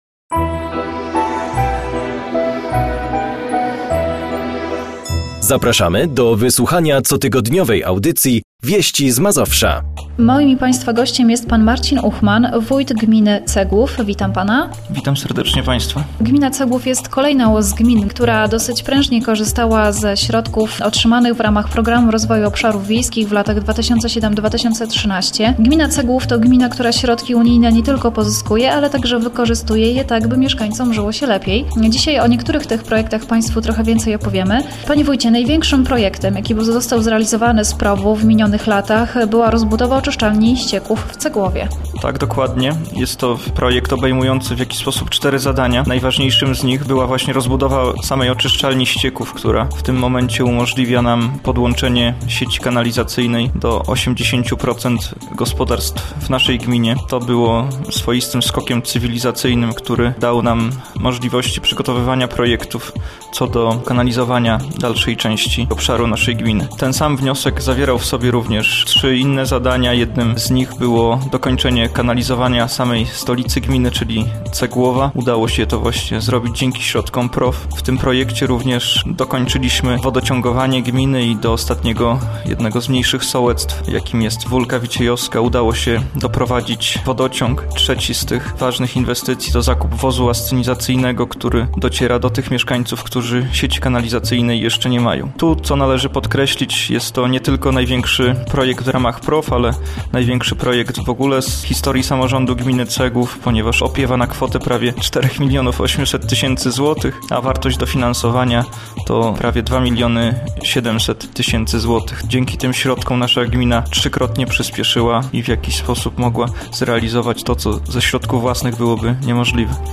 O NASZEJ GMINIE W KATOLICKIM RADIU PODLASIA - Cegłów